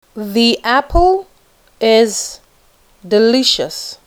When the word begins with a vowel sound, “the” is pronounce /ði/ the 2.